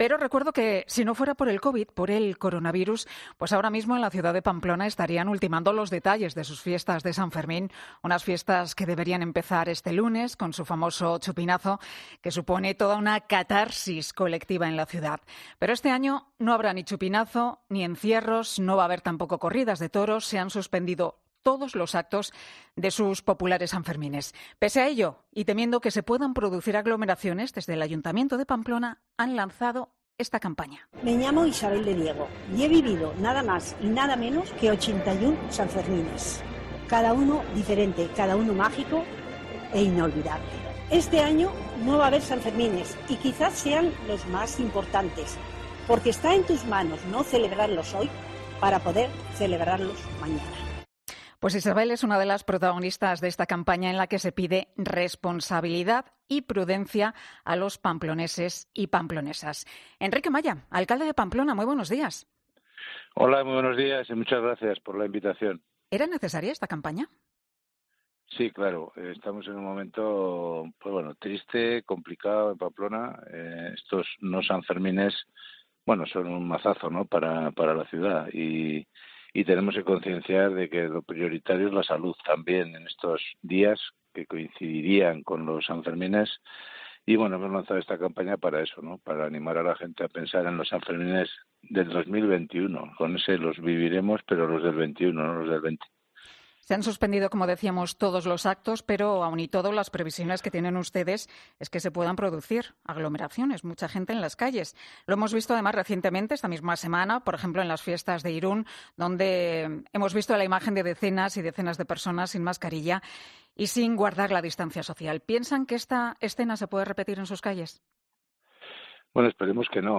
El alcalde ha apelado en 'Herrera en COPE' a la responsabilidad individual para que no se acuda a la capital navarra tras cancelarse sus fiestas